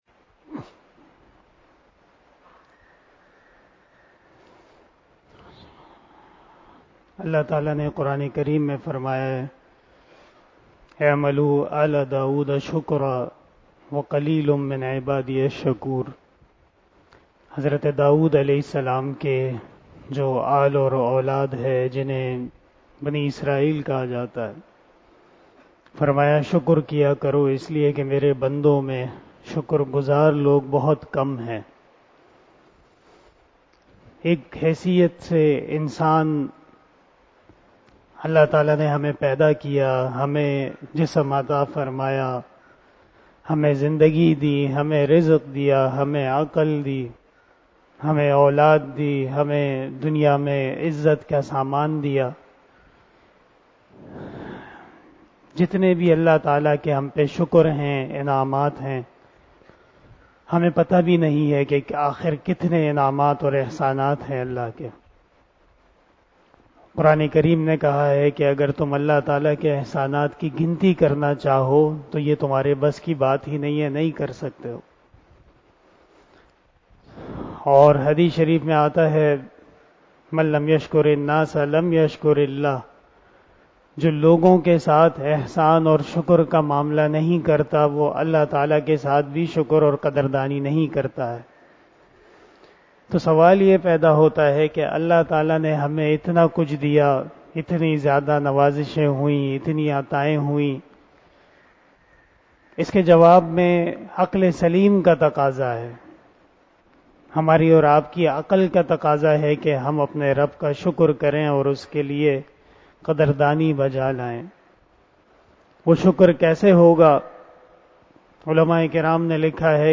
040 After Asar Namaz Bayan 14 April 2022 ( 13 Ramadan 1443HJ) Thursday